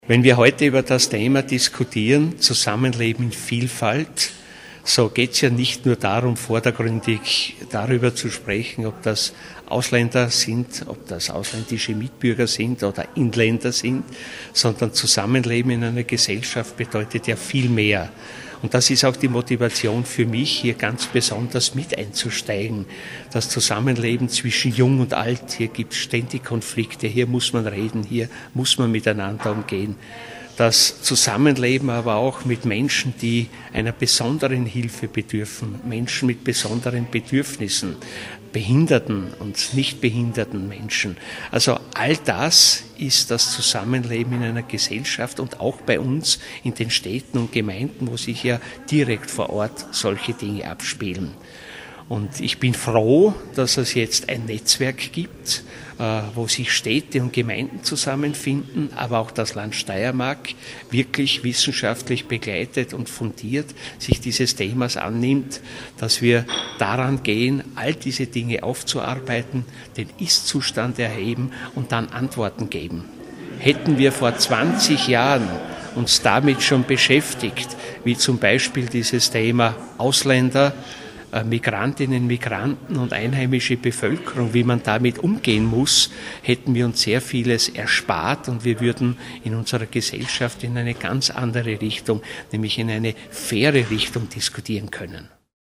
O-Ton: Partnerschaften mit dem Integrationsressort
Bernd Rosenberger, Städtebund